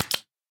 Minecraft Version Minecraft Version 25w18a Latest Release | Latest Snapshot 25w18a / assets / minecraft / sounds / mob / guardian / flop4.ogg Compare With Compare With Latest Release | Latest Snapshot
flop4.ogg